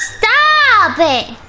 Worms speechbanks
Leavemealone.wav